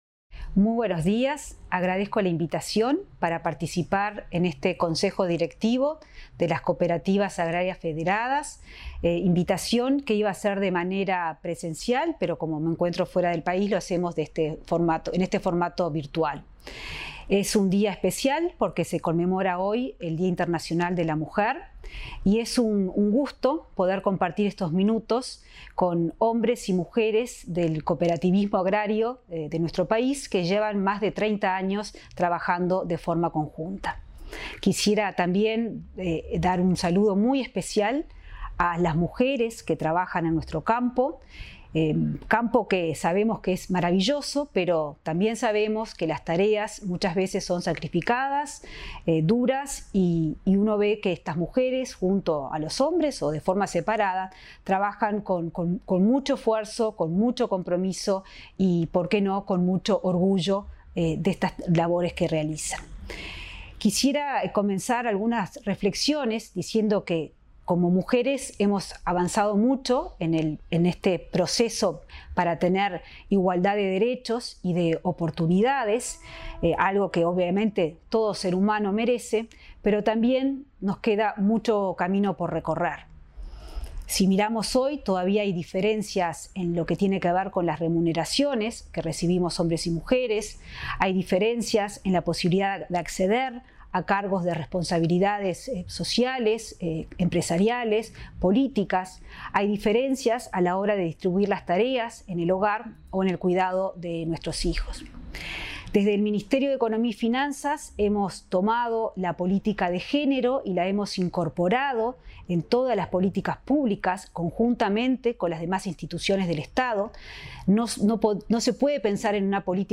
Palabras de la ministra de Economía, Azucena Arbeleche 08/03/2022 Compartir Facebook X Copiar enlace WhatsApp LinkedIn La ministra de Economía, Azucena Arbeleche, habló este martes 8, de forma virtual, en un acto de las Cooperativas Agrarias Federadas, entidad que distinguió la labor de mujeres que ejercen roles de liderazgo.